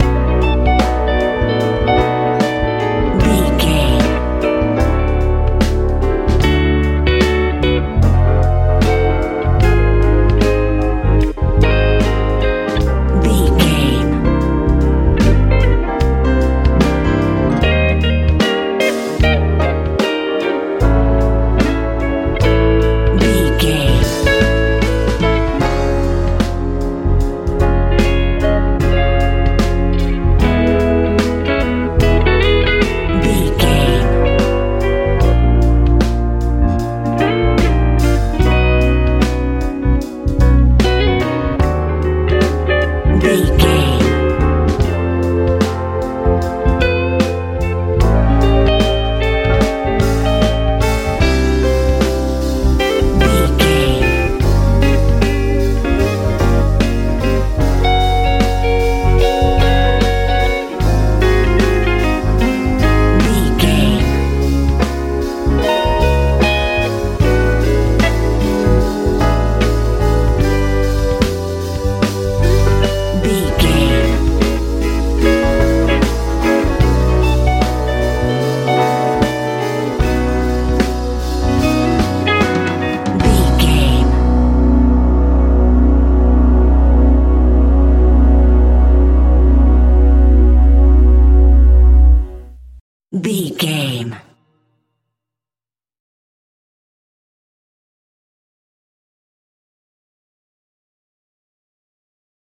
modern rnb soul feeling music
Ionian/Major
calm
electric guitar
piano
drums
bass guitar
happy
inspirational
relaxed